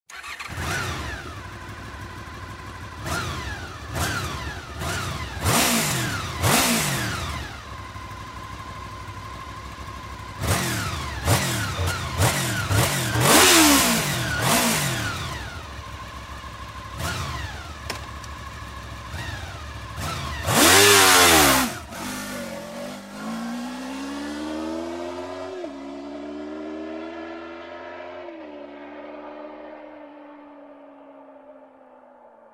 Ducati ST2, 1000cc med originalsystem
Ducati_ST2.mp3